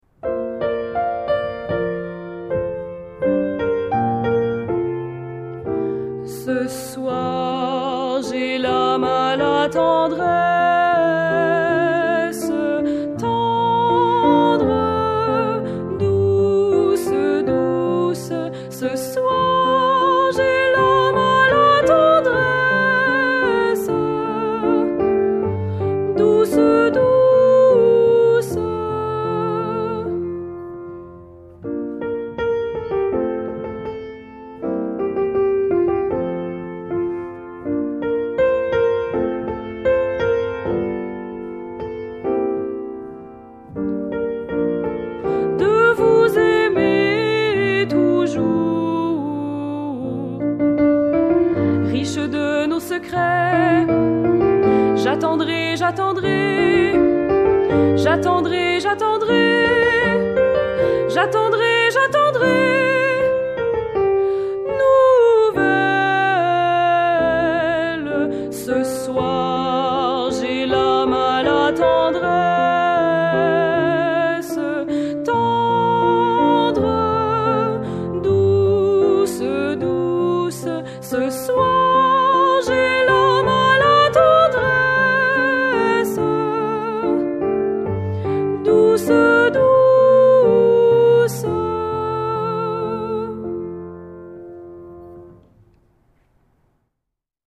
Audio voix 1